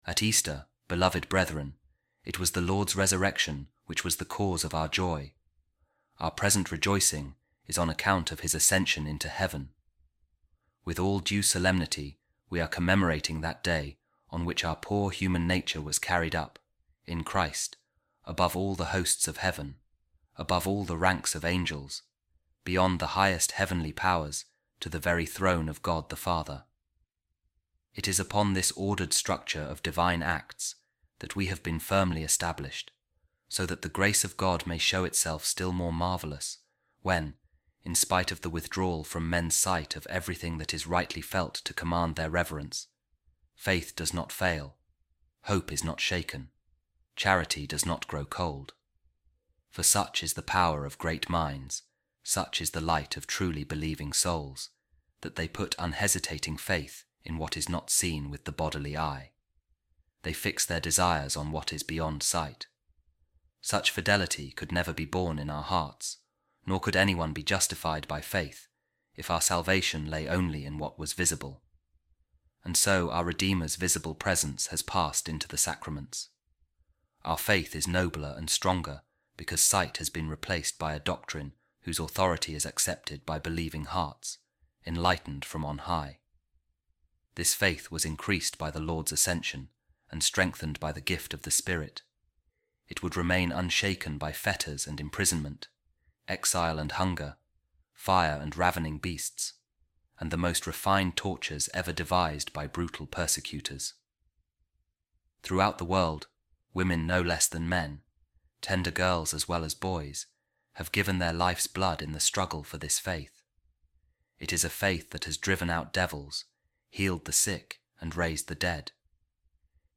Office Of Readings | Eastertide Week 6, Friday After The Ascension | A Reading From The Sermons Of Pope Saint Leo The Great | The Lord’s Ascension Increases Our Faith